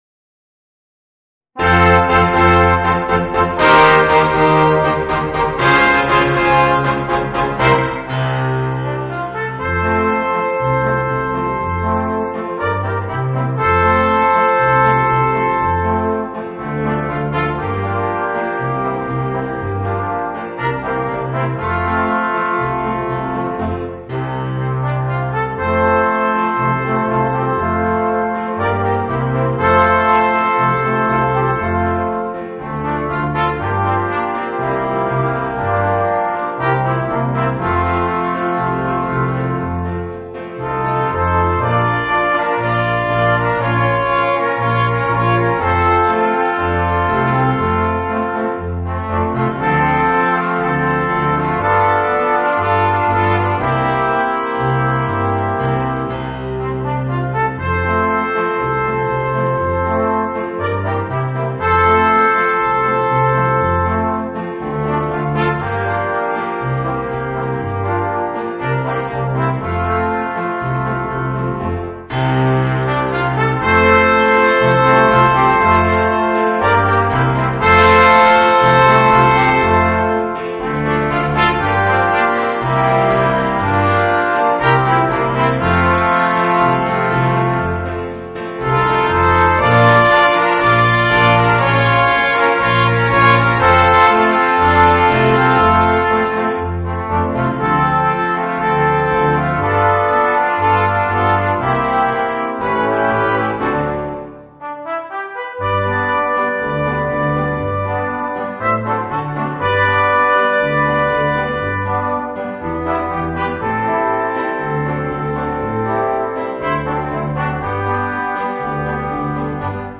Voicing: 5 - Part Ensemble and Rhythm Section